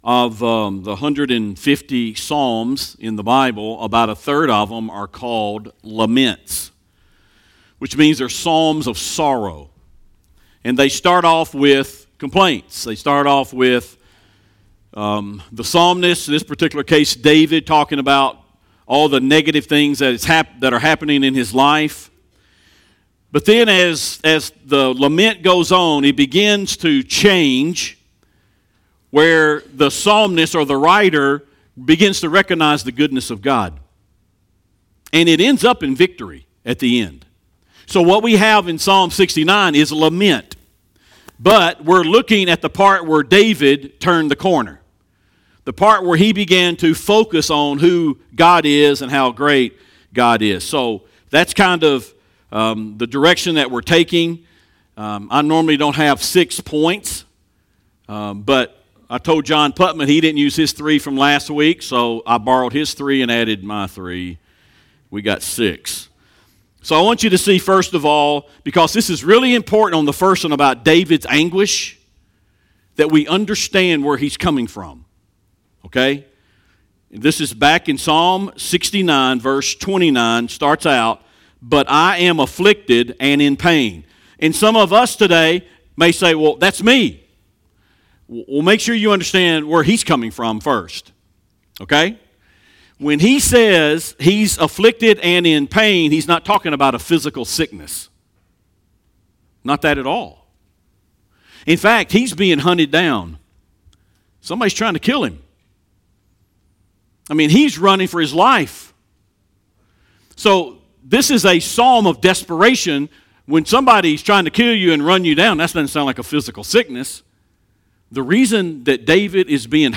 A message from the series "Turn the Corner."